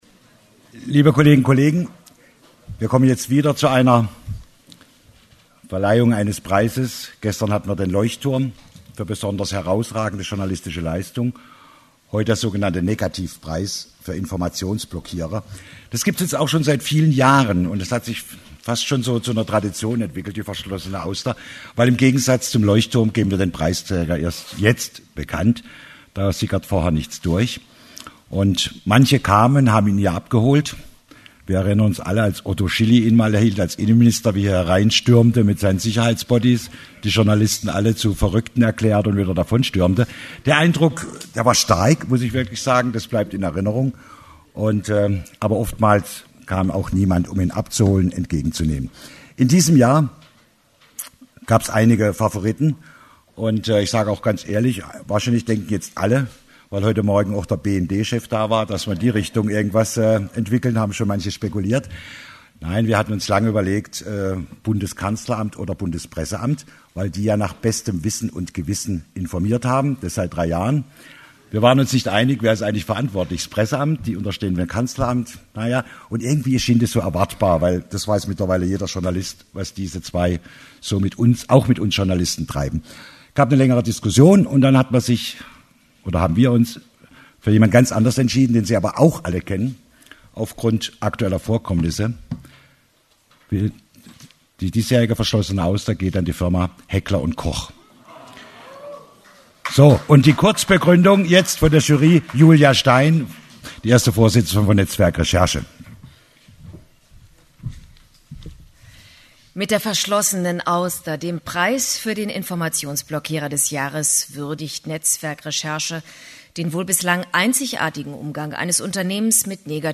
Was: Verleihung „Verschlossene Auster“ 2015, Jahrestreffen netzwerk recherche Wo: Hamburg, NDR-Fernsehzentrum Wann